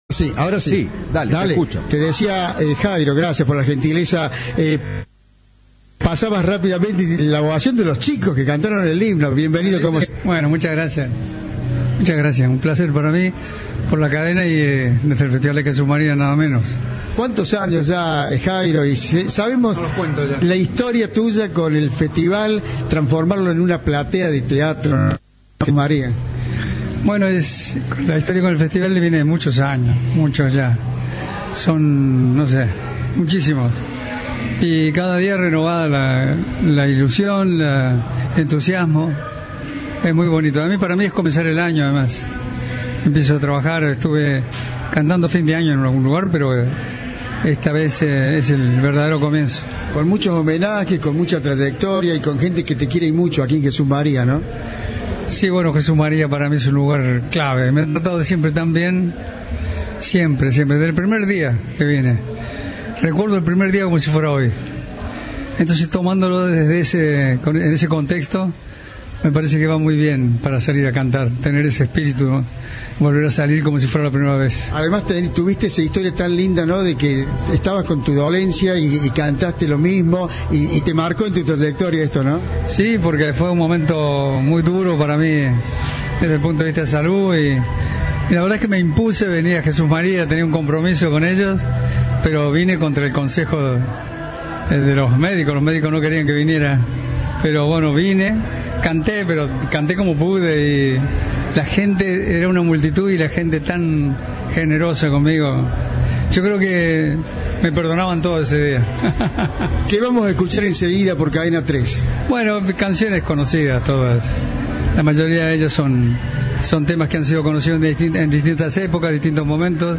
Audio. Jairo recordó su primera noche en Jesús María antes de subir al escenario